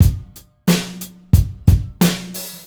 • 90 Bpm Drum Loop Sample F# Key.wav
Free breakbeat - kick tuned to the F# note. Loudest frequency: 1541Hz
90-bpm-drum-loop-sample-f-sharp-key-DB3.wav